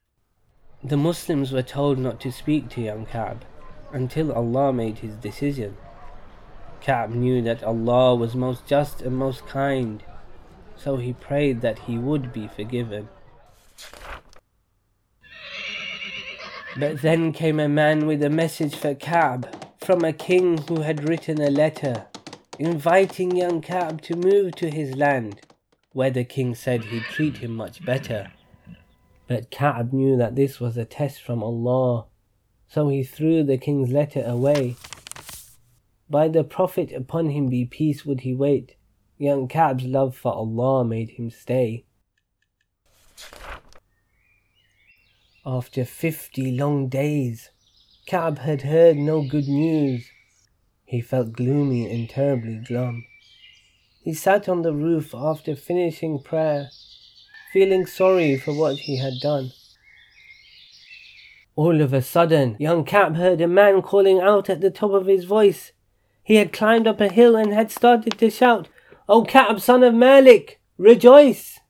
The story has been brought to life with rhyming prose and attractive illustrations, enhancing the teaching of the seerah of the Prophet (sallallahu ‘alayhi wa sallam) and the ethical lessons of truth and honesty therein.
Audiobooks are available for each of our storybooks. A sample is included here: